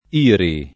vai all'elenco alfabetico delle voci ingrandisci il carattere 100% rimpicciolisci il carattere stampa invia tramite posta elettronica codividi su Facebook IRI [ & ri ] n. pr. m. — sigla di Istituto per la Ricostruzione Industriale (1933-2000)